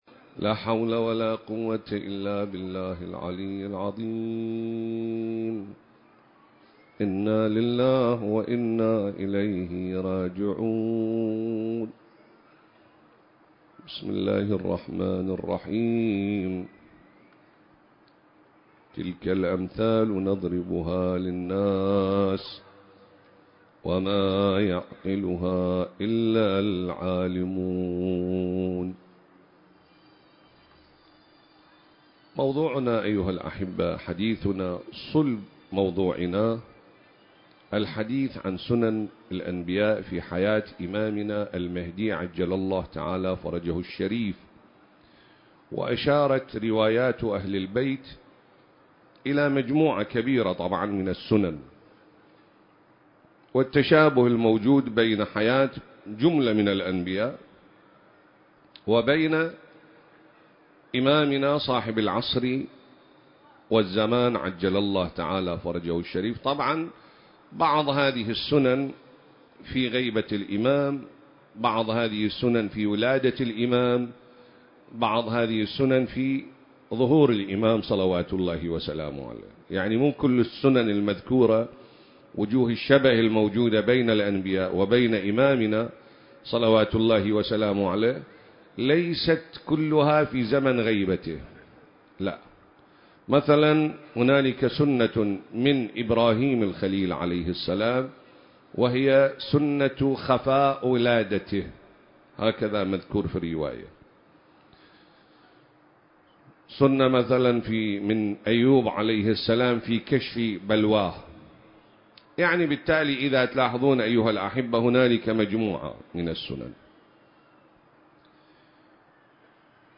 سلسلة: تشابه سنن الأنبياء (عليهم السلام) والإمام المهدي (عجّل الله فرجه) (4) المكان: العتبة العسكرية المقدسة التاريخ: 2024